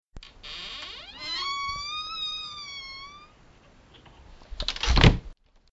PUERTA CHIRRIANTE PUERTA ABRIENDOSE
EFECTO DE SONIDO DE AMBIENTE de PUERTA CHIRRIANTE PUERTA ABRIENDOSE
Puerta_Chirriante_-_Puerta_Abriendose.mp3